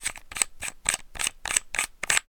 Add bateries to Flashlight.wav